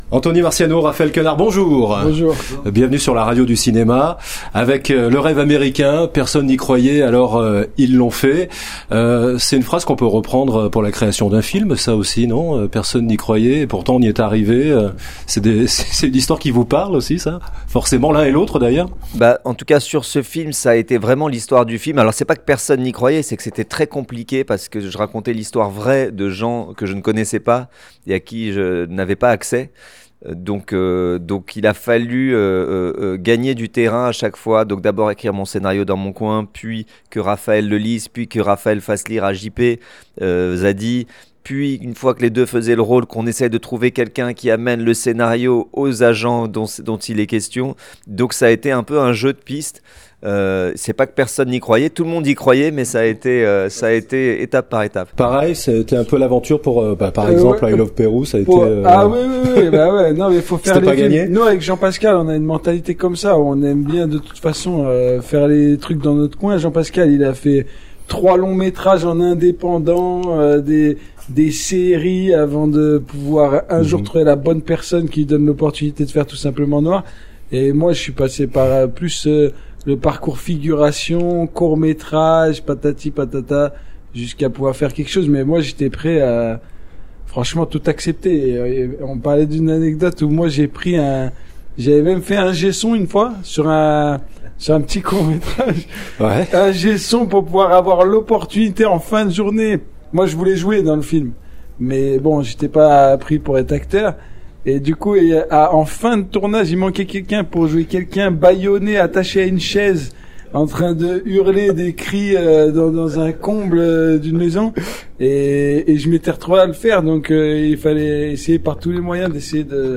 Le rêve américain : Raphaël Quenard et Anthony Marciano, l’interview